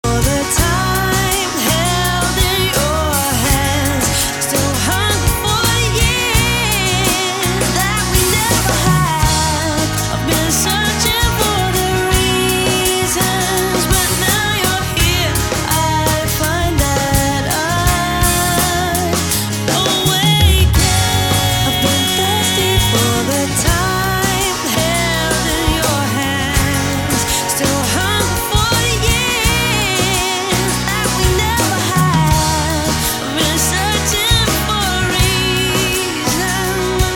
distinctive silky, soulful voice
acoustic guitar
Alternative,New Age